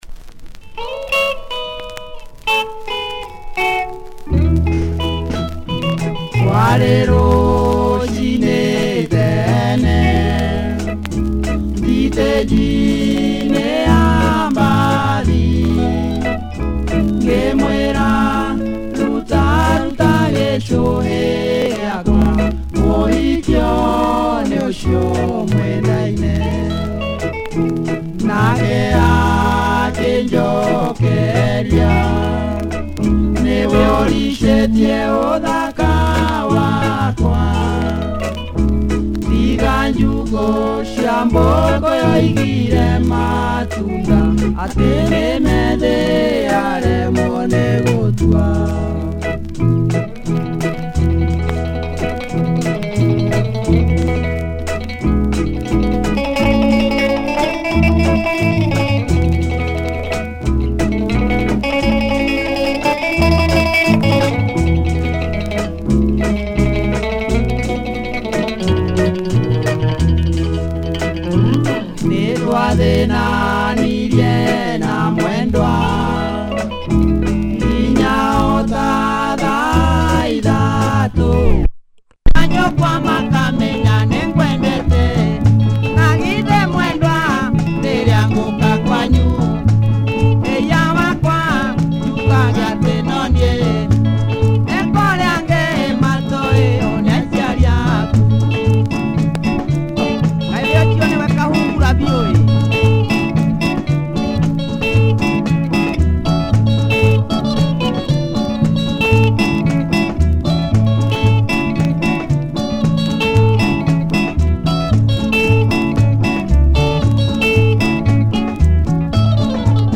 Traditional sounds